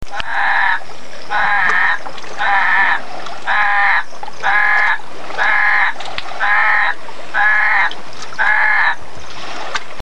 La nuit tombée, quelques appels rauques du crapaud guttural, Bufo gutturalis, semblent sortir de nulle part du bord de l'eau, mais ils sont localisés entre les énormes roches bordant la plage.
Surprise nocturne derrière les bungalows, évacuation des eaux = humidité.
De nombreux crapauds gutturaux, Bufo gutturalis:
gutturalis.mp3